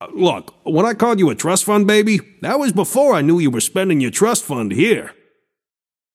Shopkeeper voice line - Look, when I called you a trust fund baby, that was before I knew you were spending your trust fund here.